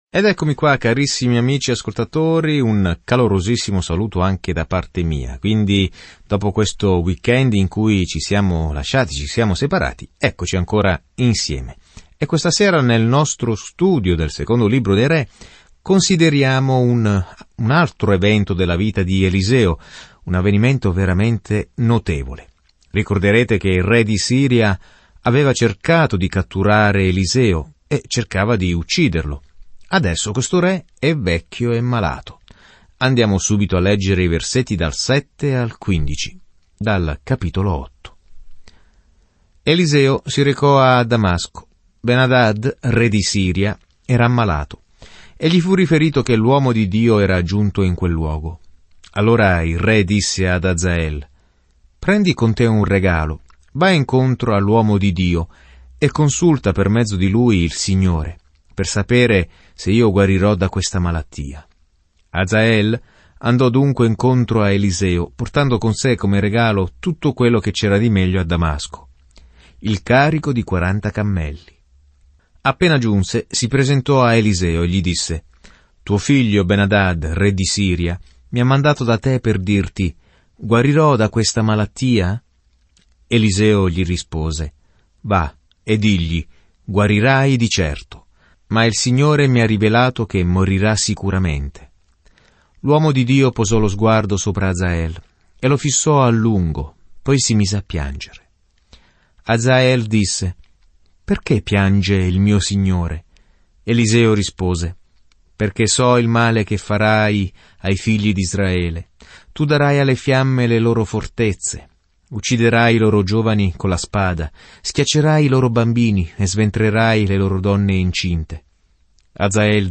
Scrittura Secondo libro dei Re 8:7-29 Secondo libro dei Re 9 Giorno 5 Inizia questo Piano Giorno 7 Riguardo questo Piano Il libro di Secondo Re racconta come le persone persero di vista Dio e come Egli non le dimenticò mai. Viaggia ogni giorno attraverso 2 Re mentre ascolti lo studio audio e leggi versetti selezionati della parola di Dio.